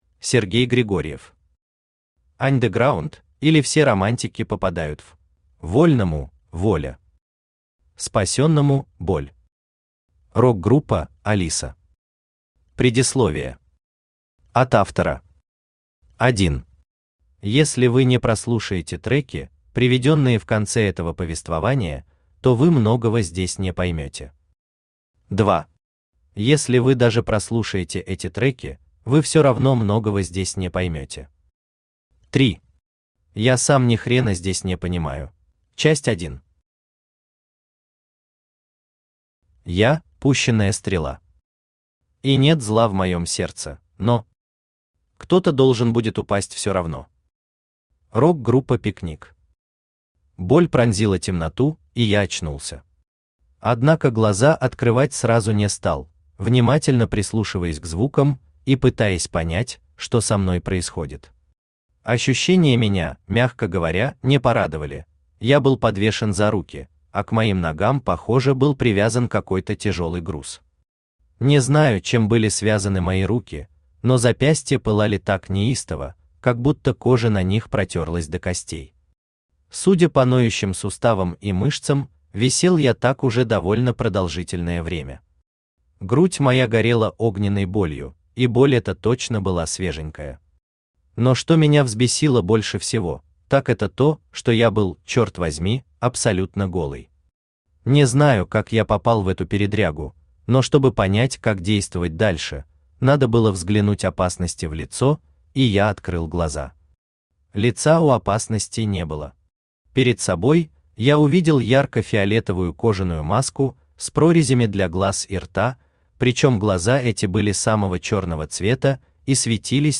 Аудиокнига Андеграунд, или Все романтики попадают в..
Автор Сергей Григорьев Читает аудиокнигу Авточтец ЛитРес.